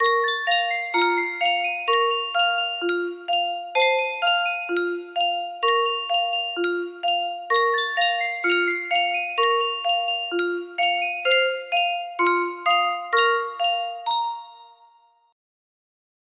Sankyo Music Box - Joshua Fit the Battle of Jericho FGR music box melody
Full range 60